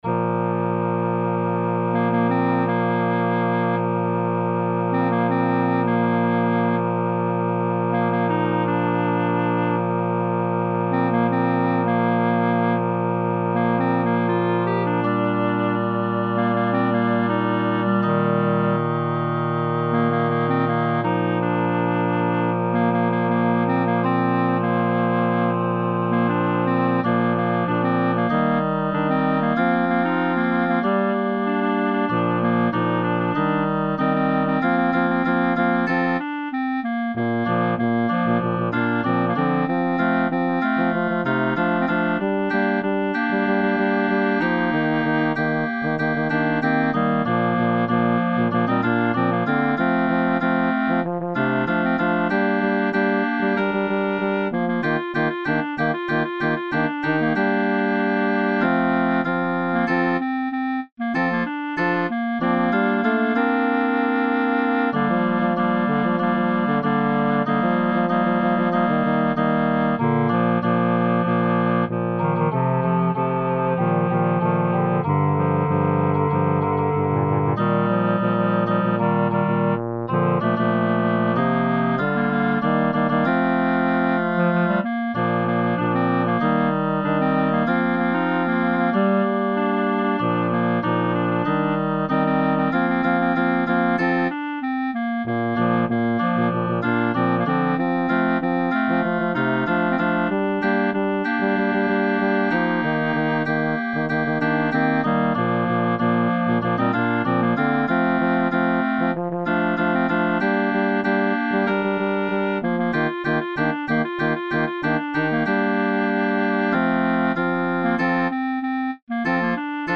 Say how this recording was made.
Upper Voices Performance